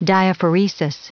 Prononciation du mot diaphoresis en anglais (fichier audio)
Prononciation du mot : diaphoresis
diaphoresis.wav